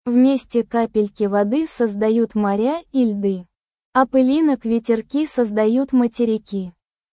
Голос «Маргарита», синтез речи нейросетью